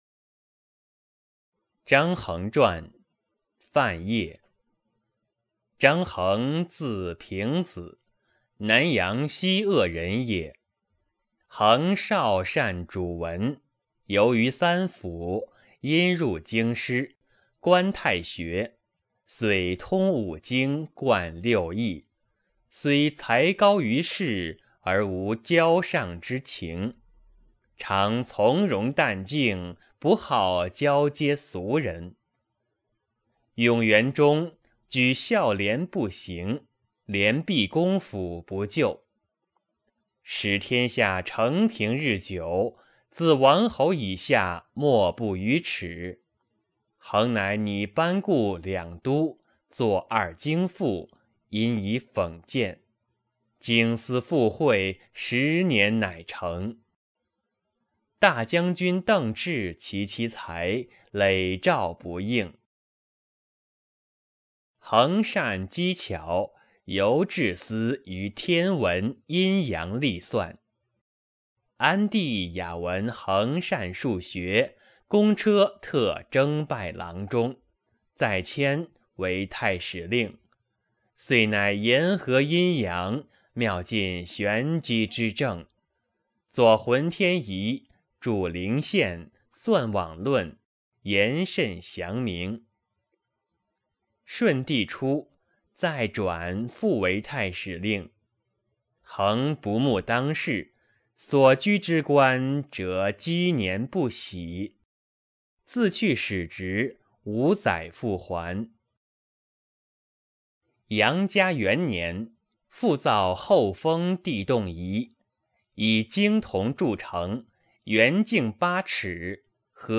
《张衡传》原文和译文（含朗读）